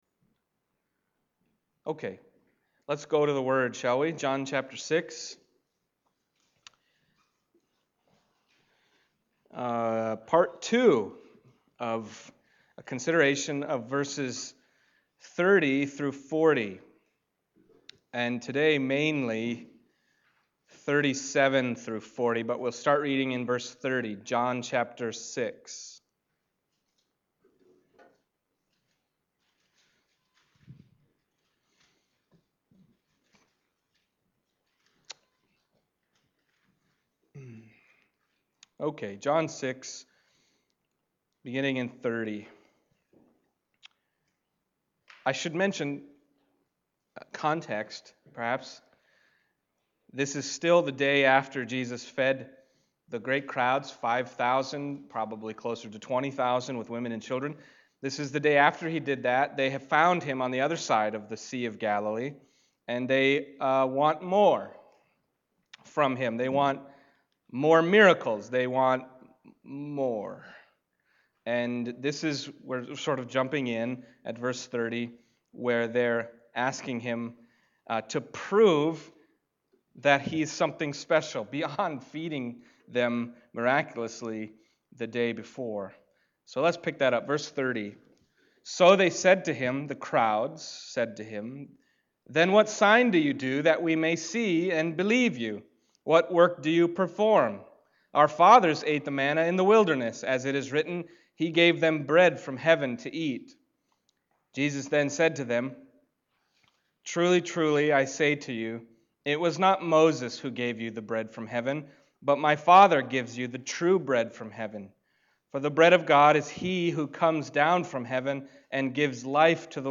Passage: John 6:37-40 Service Type: Sunday Morning